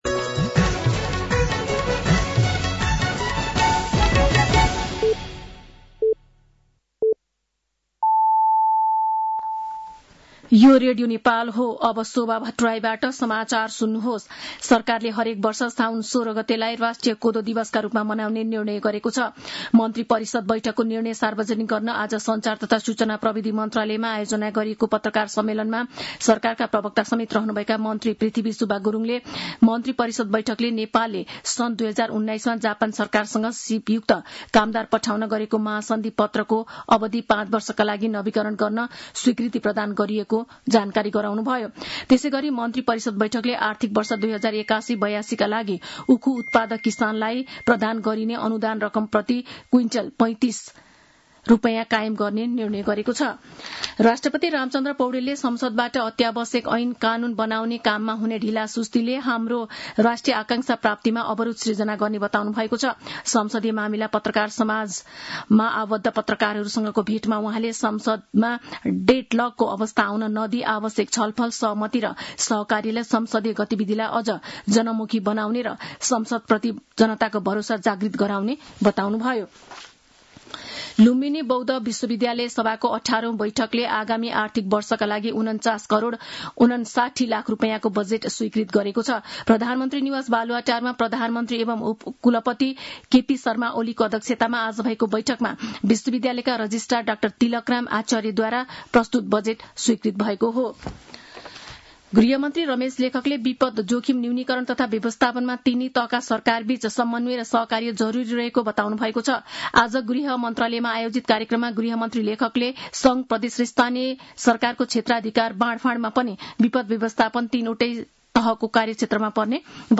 साँझ ५ बजेको नेपाली समाचार : ३२ असार , २०८२
5-pm-news-3-32.mp3